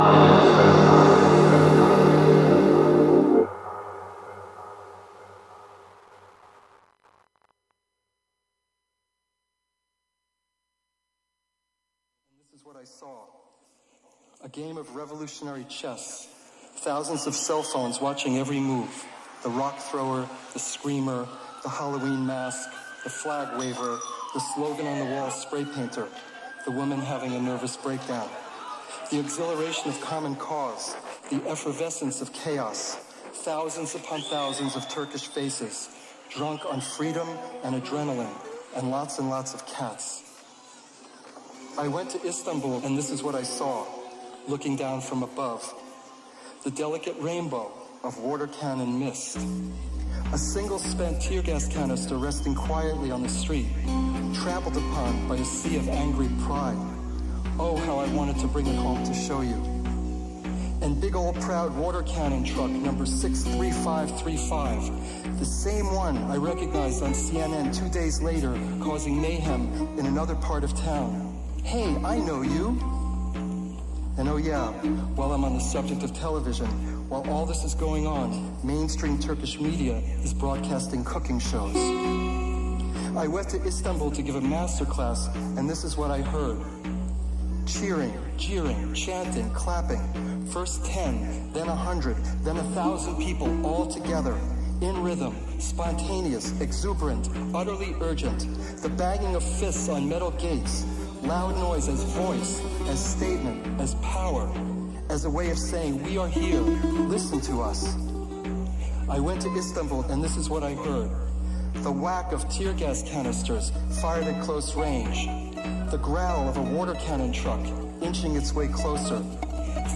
Dream Pop Shoegaze Trip Hop